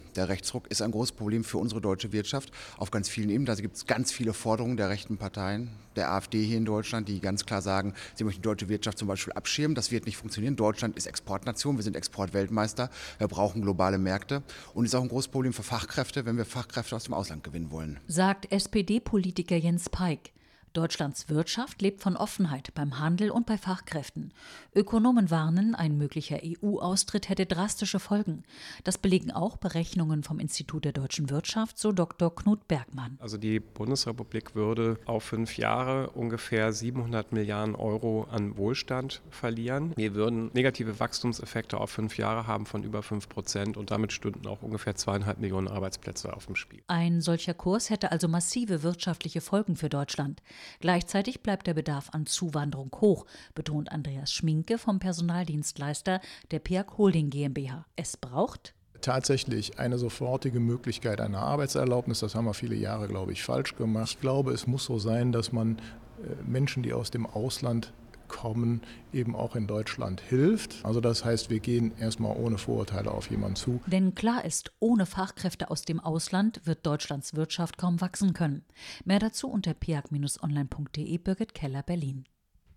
Magazin: Rechtspopulismus gefährdet Wirtschaftsstandort Deutschland